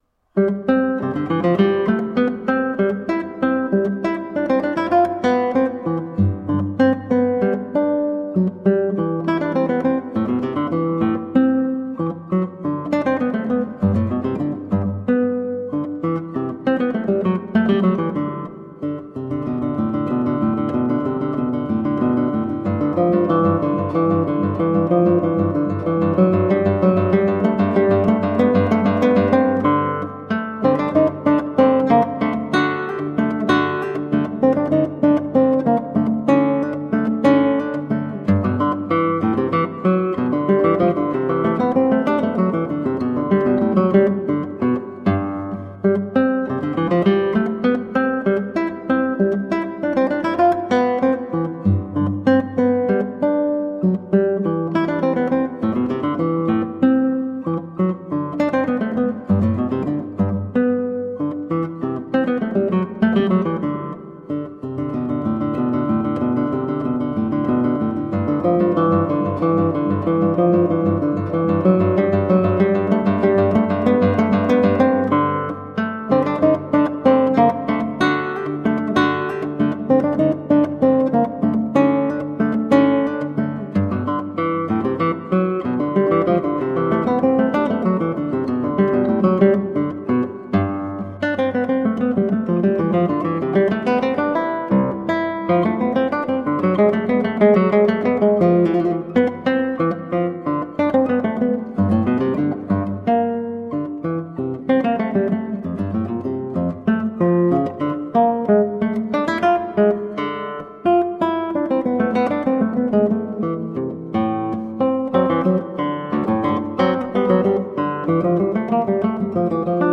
Tagged as: Classical, Baroque, Instrumental
Classical Guitar